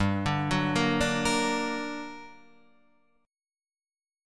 GmM7 chord